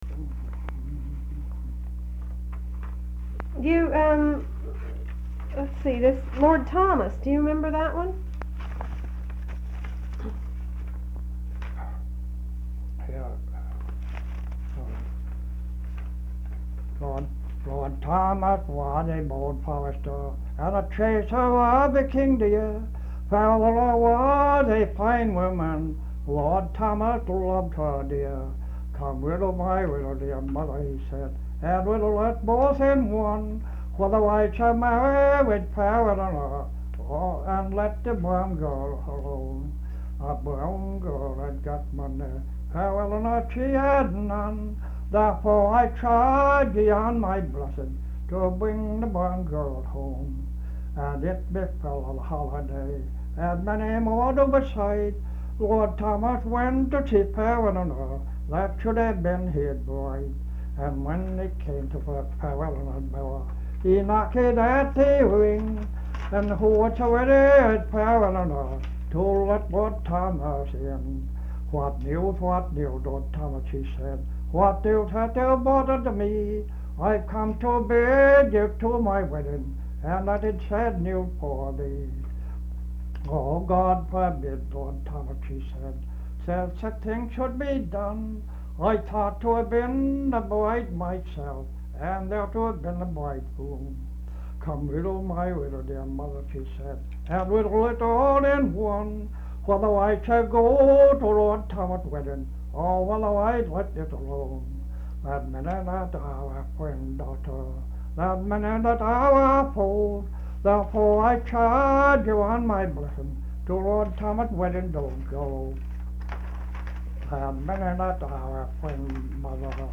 Folk songs, English--Vermont (LCSH)
sound tape reel (analog)